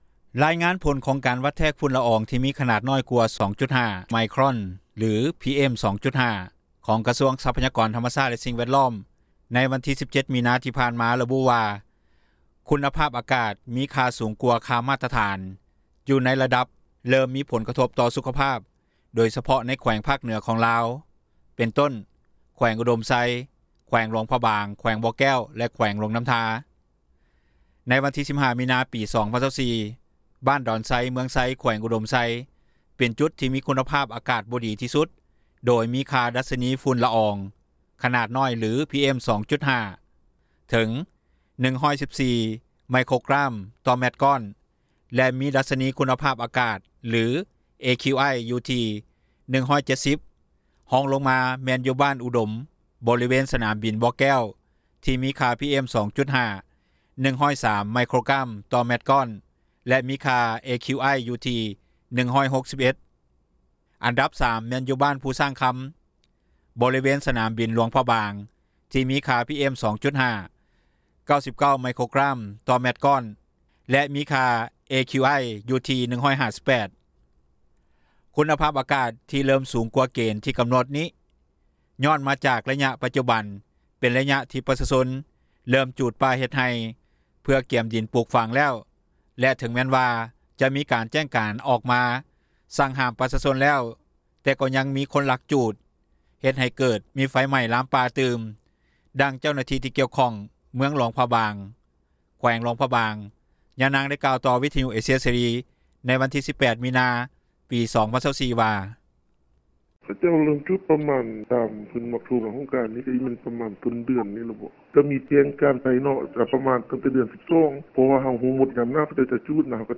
ດັ່ງເຈົ້າໜ້າທີ່ ທີ່ກ່ຽວຂ້ອງຢູ່ແຂວງຫຼວງນໍ້າທາ ຍານາງກ່າວວ່າ: